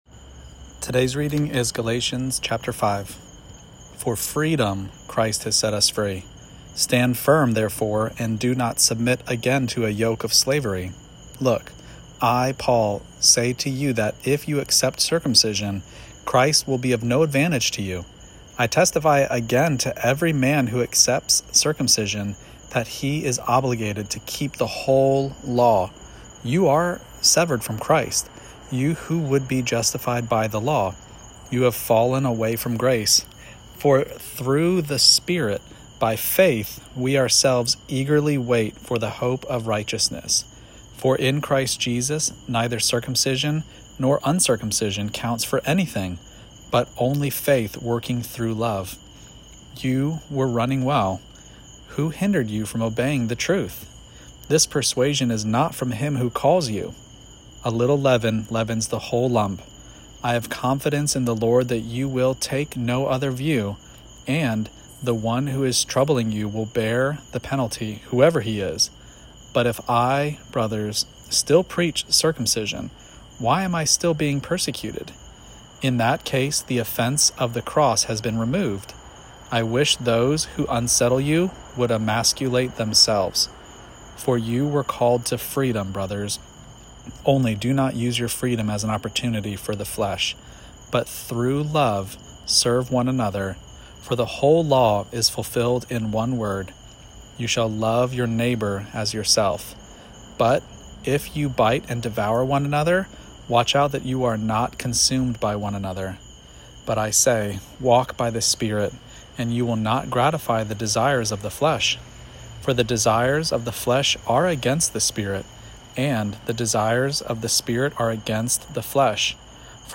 Daily Bible Reading (ESV) September 26: Galatians 5 Play Episode Pause Episode Mute/Unmute Episode Rewind 10 Seconds 1x Fast Forward 30 seconds 00:00 / 3:13 Subscribe Share Apple Podcasts Spotify RSS Feed Share Link Embed